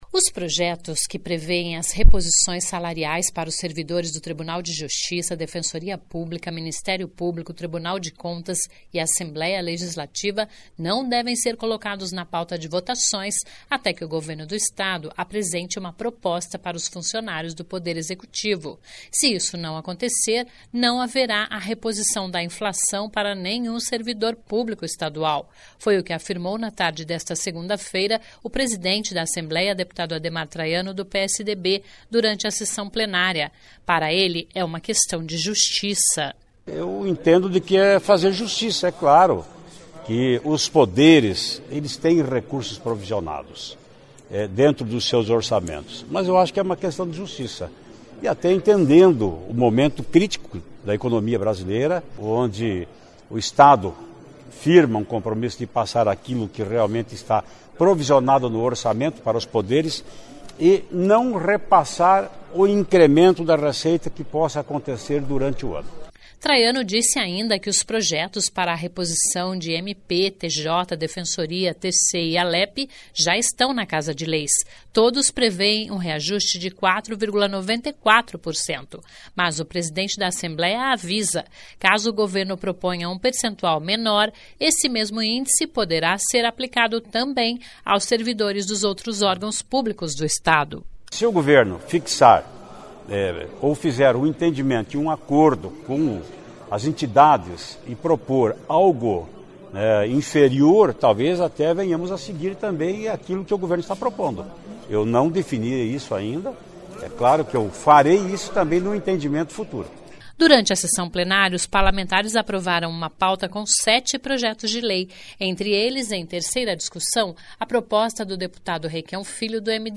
Se isso não acontecer, não haverá a reposição da inflação para nenhum servidor público estadual. Foi o que afirmou na tarde desta segunda-feira (1º) o presidente da Assembleia, deputado Ademar Traiano (PSDB), durante a Sessão Plenária.